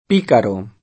p&karo] s. m.; pl. pícaros [